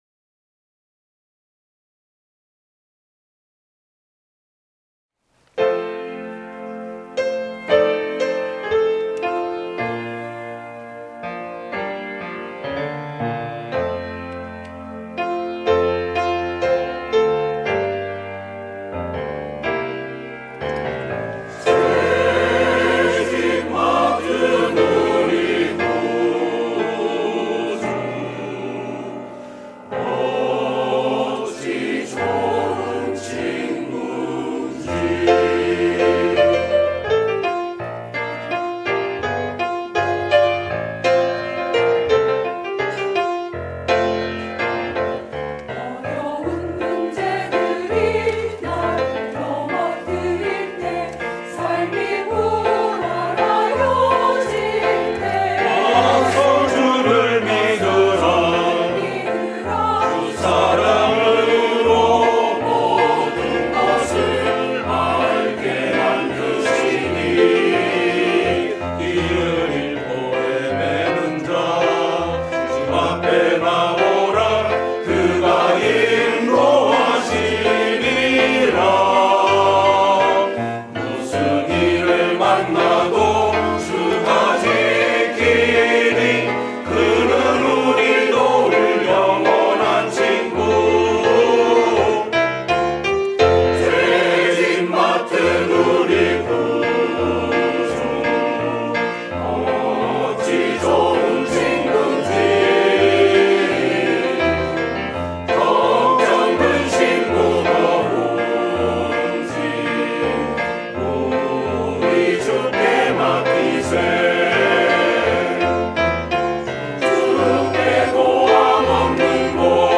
갈릴리